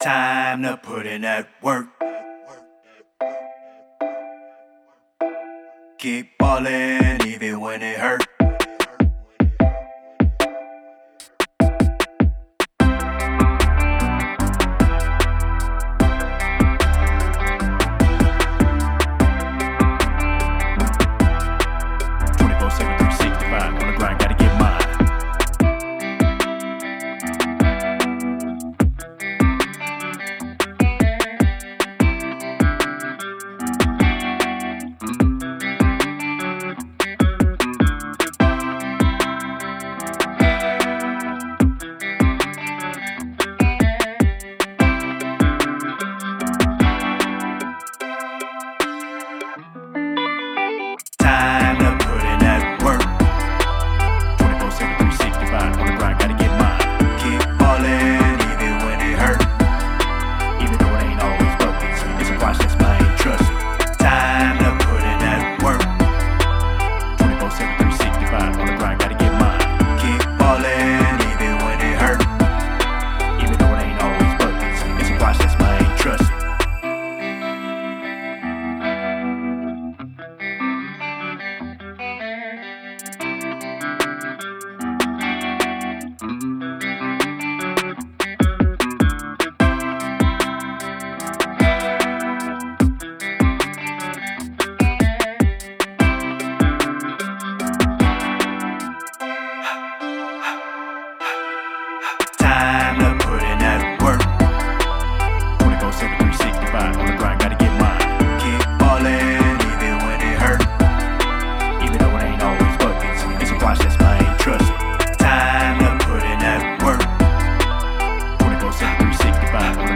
Production Music Examples
Hip Hop (Sports)